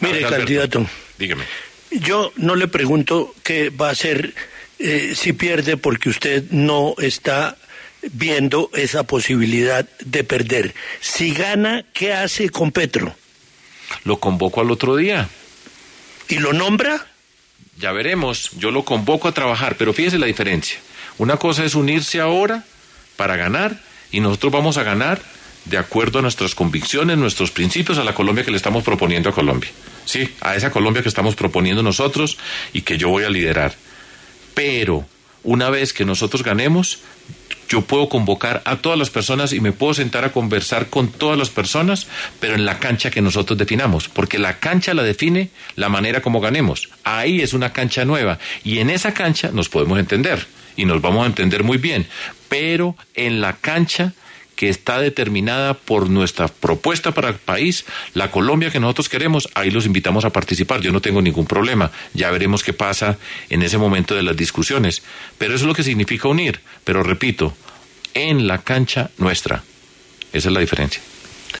En entrevista con La W, el precandidato presidencial de la Coalición Centro Esperanza Sergio Fajardo, habló sobre las posibilidades de trabajar con Gustavo Petro en caso de ganar en segunda vuelta.